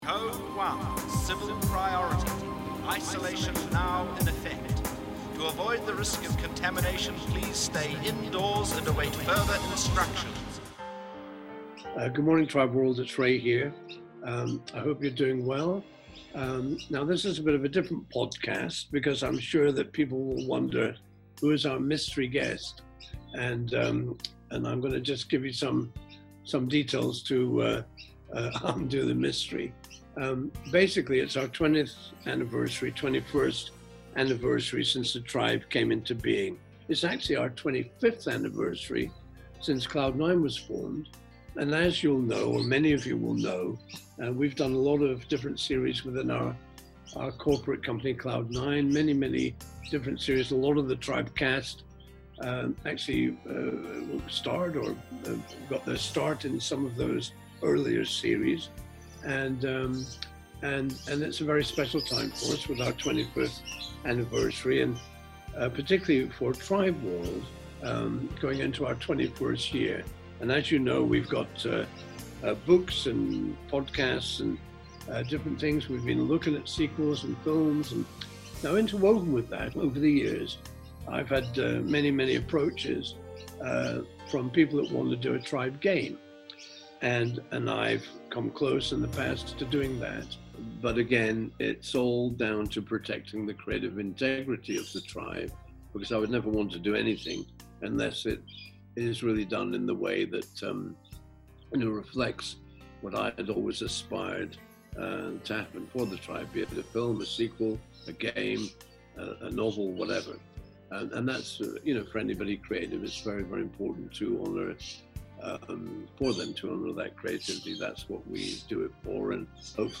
The Tribe - Mystery Guest - Interview - Virus Lockdown - The Tribe Official TV Series Podcast